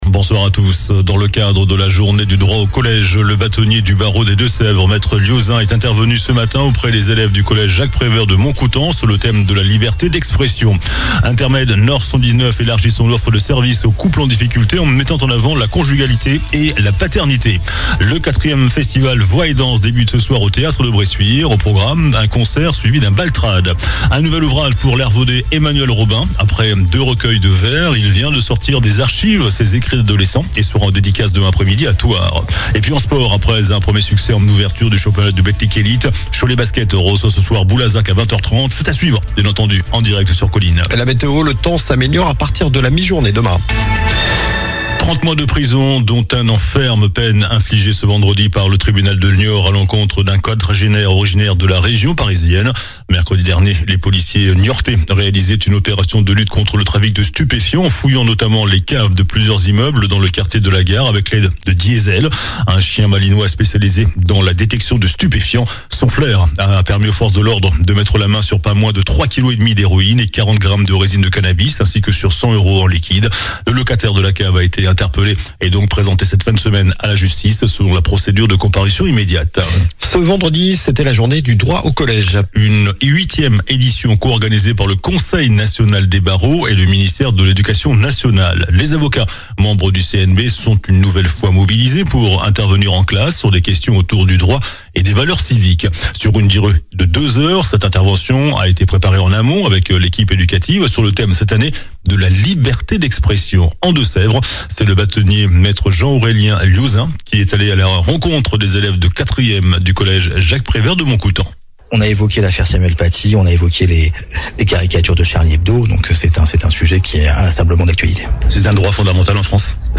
COLLINES LA RADIO : Réécoutez les flash infos et les différentes chroniques de votre radio⬦
JOURNAL DU VENDREDI 03 OCTOBRE ( SOIR )